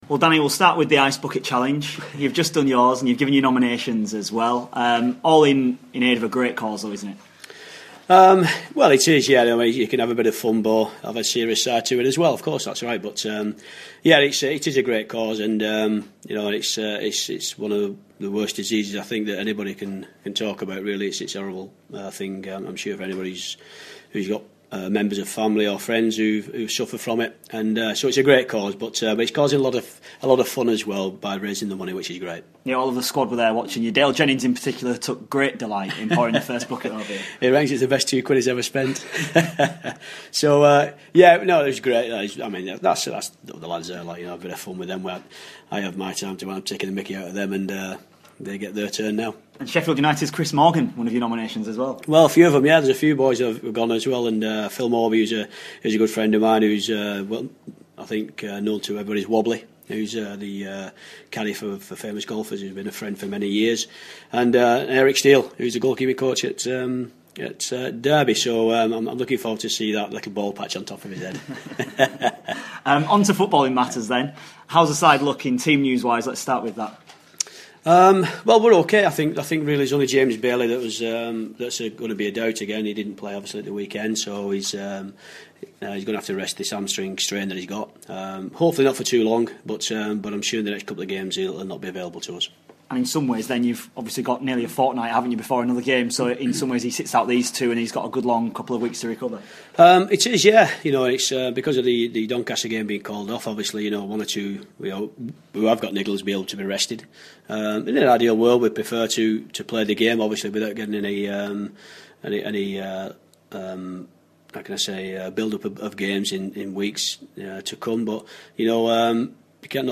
INTERVIEW: Barnsley manager Danny Wilson speaking ahead of his sides trip to Yeovil.